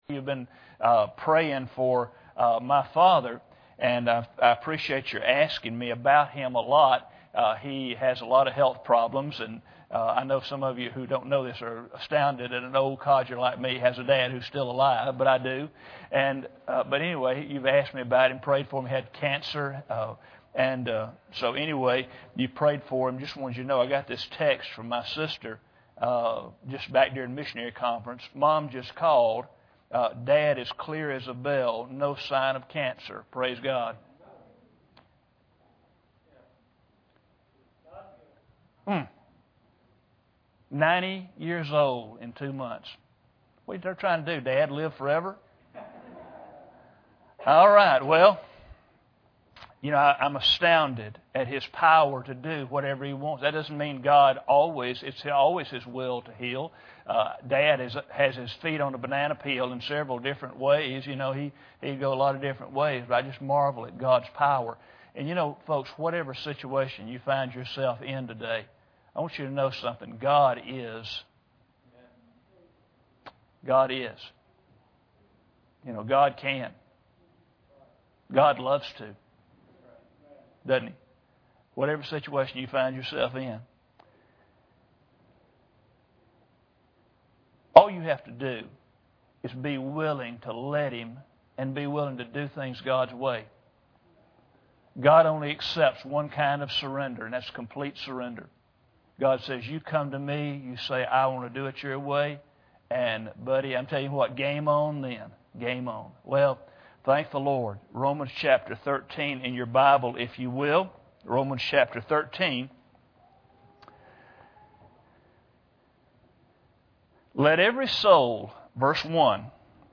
Sermon on the proper Christian response to authority (specifically government) and doing things God’s way.